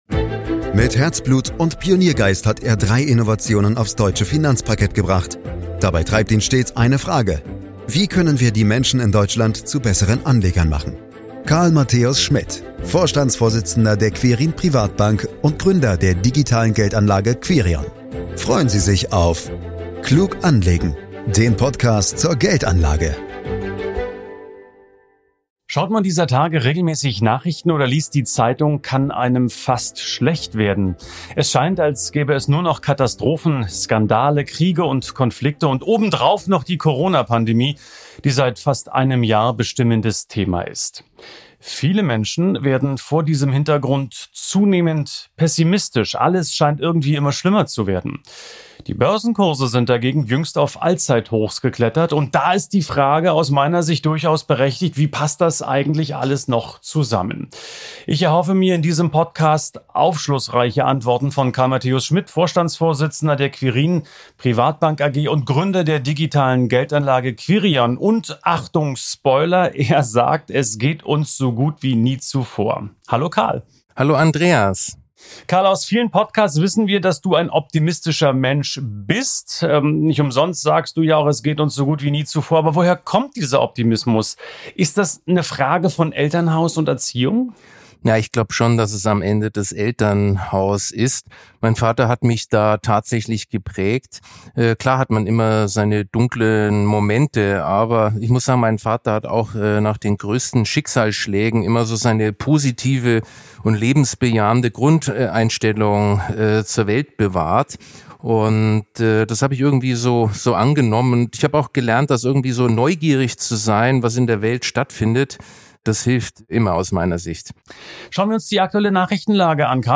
Im Gespräch dazu